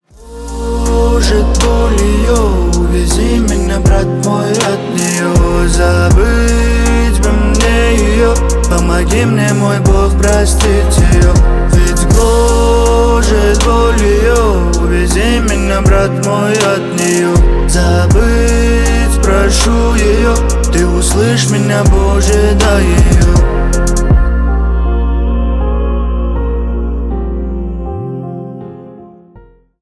Поп Музыка
грустные # спокойные # тихие